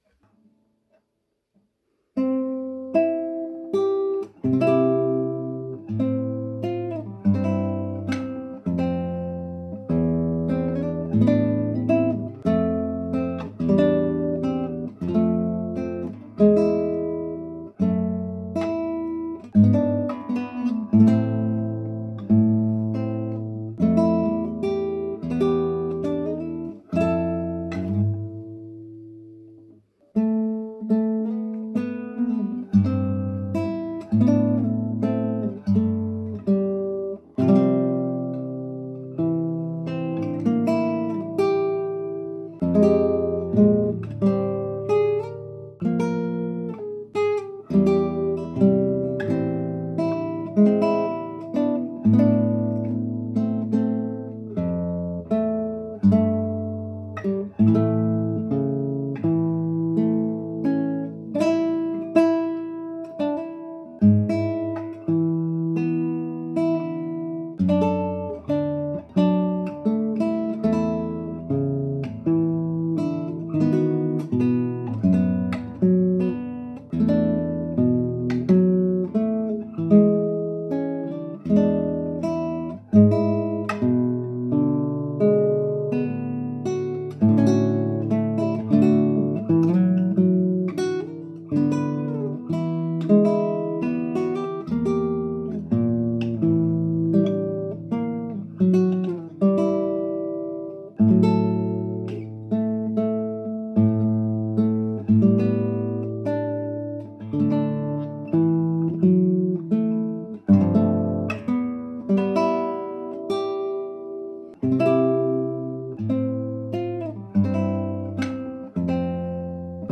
classical guitar arrangement
Voicing/Instrumentation: Guitar